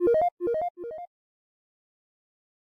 Sound Blip Echo
sound blip echo